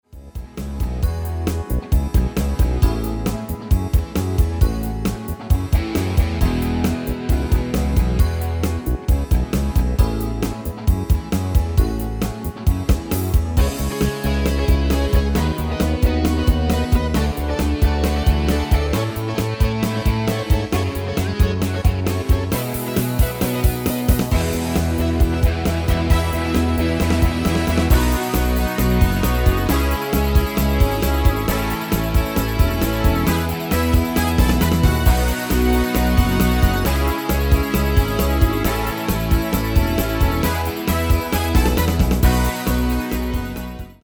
Demo/Kauf Midifile
Stil: Weihnachten
Tonart: C/D